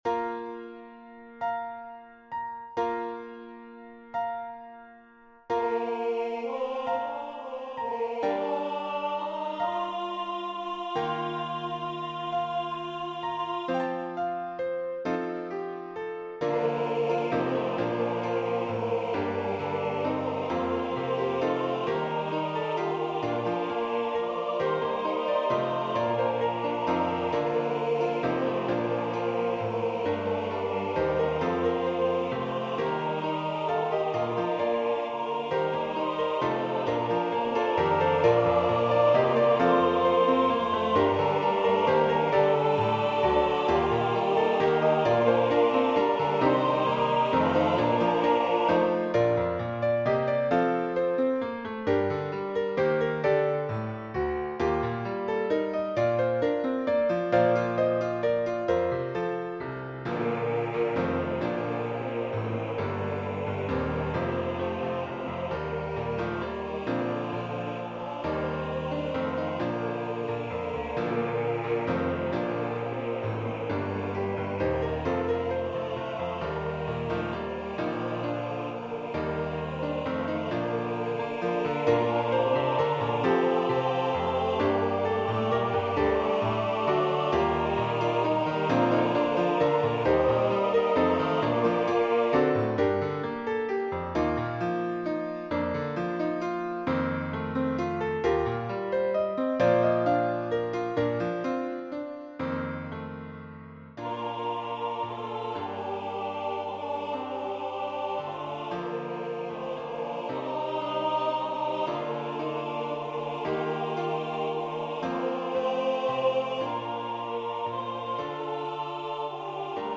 SSATB
This arrangement is in 6/4, so it has a waltz-like feel.